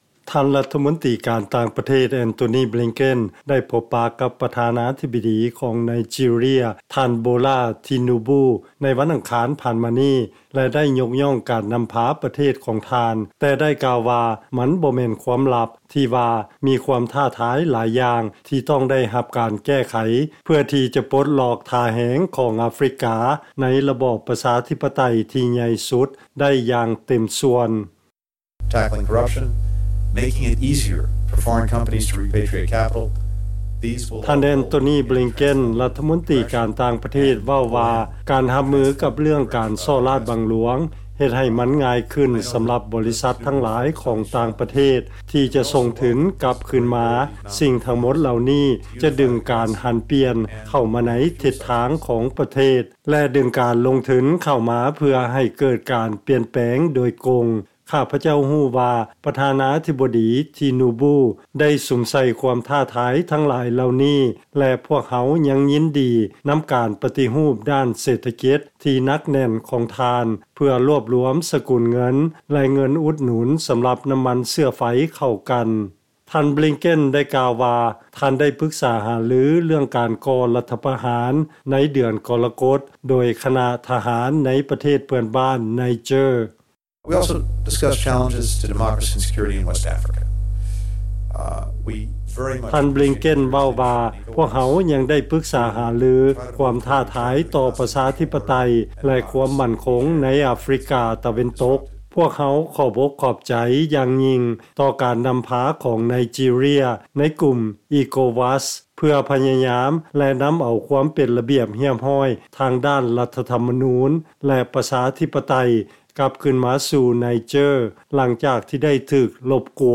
ຟັງລາຍງານ ທ່ານບລິງເກັນ ສະແດງໃຫ້ເຫັນຄວາມໝັ້ນໝາຍຂອງສະຫະລັດ ຕໍ່ອາຟຣິກາ ທ່າມກາງວິດກິດການອື່ນໆໃນໂລກ